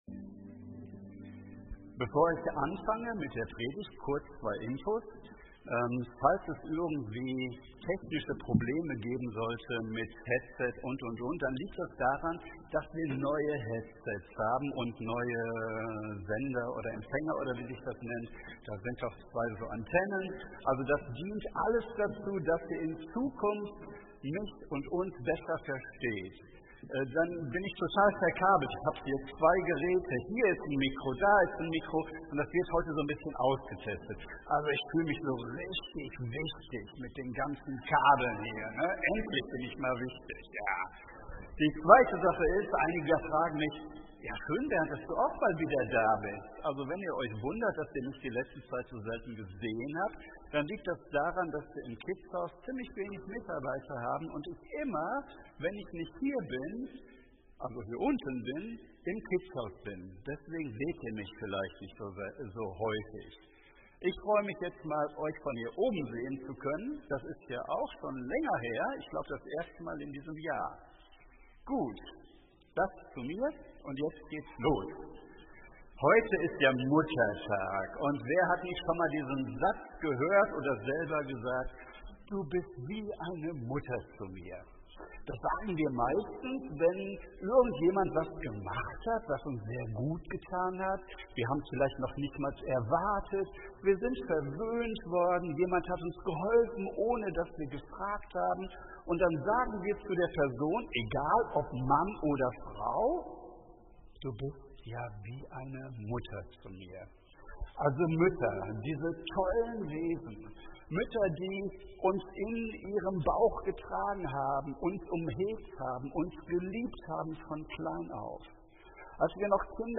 Predigt zum Muttertag